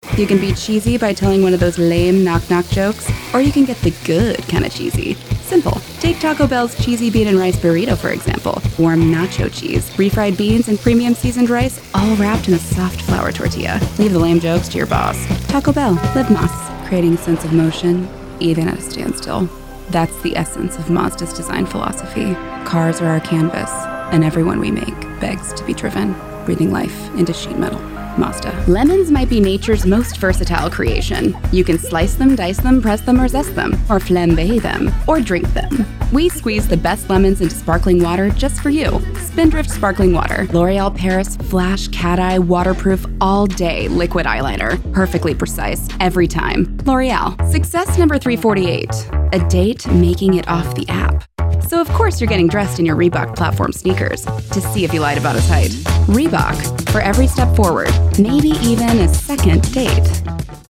British Radio & TV Commercial Voice Overs Artists
Adult (30-50) | Yng Adult (18-29)